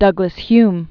(dŭgləs-hym), Sir Alexander Frederick 1903-1995.